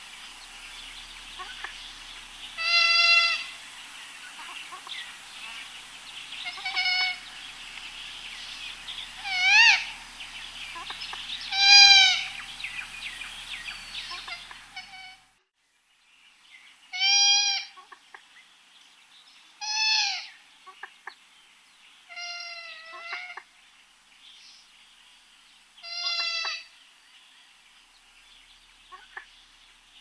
Poule d'eau - Mes zoazos
poule-d-eau.mp3